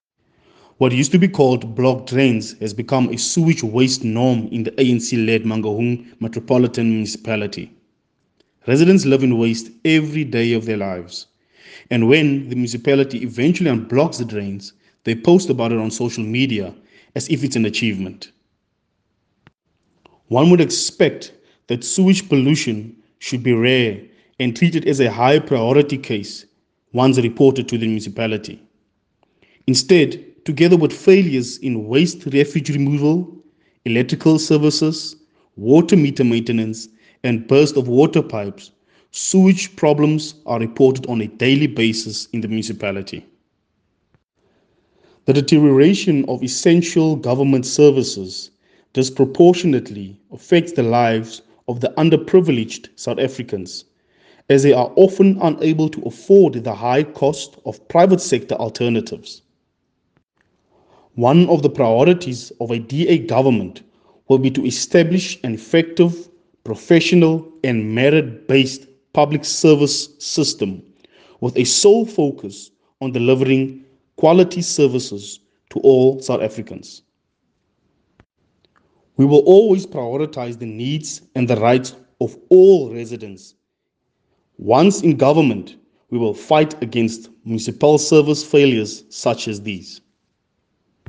English and Afrikaans soundbites by Cllr Lyle Bouwer and Sesotho by Cllr David Masoeu.